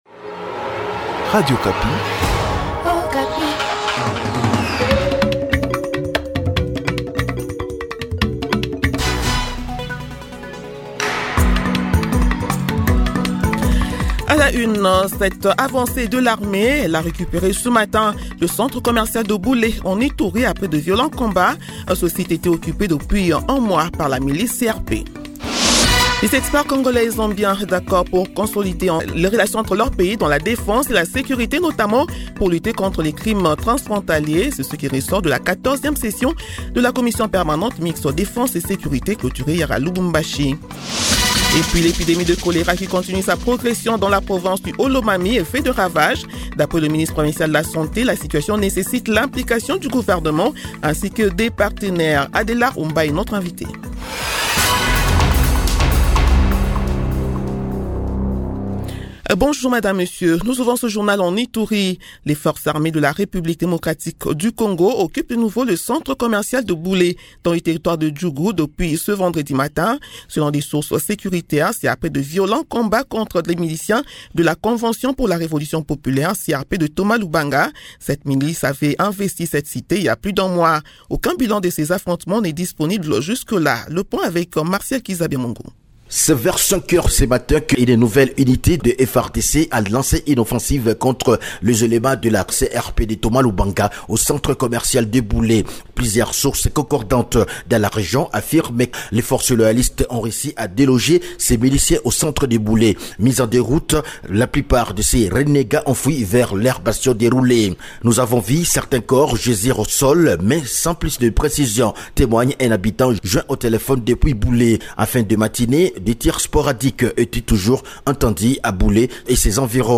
Journal midi du vendredi 16 Janvier 2026